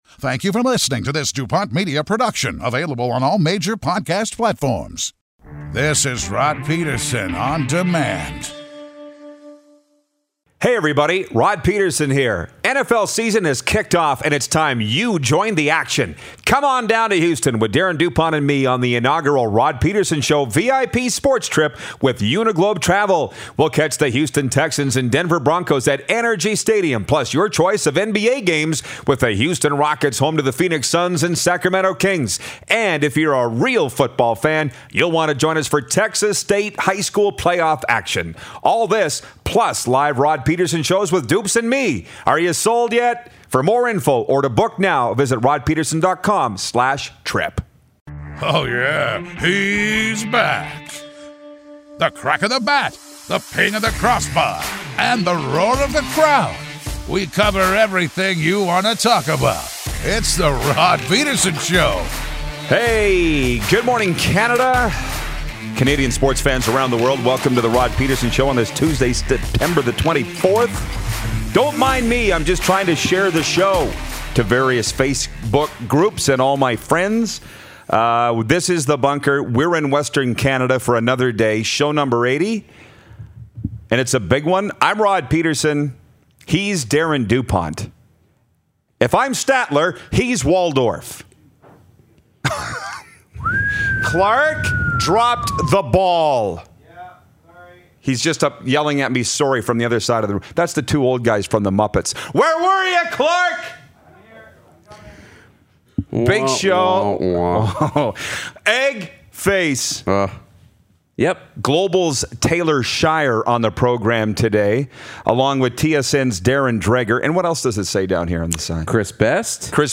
TSN Hockey Insider, Darren Dreger calls in!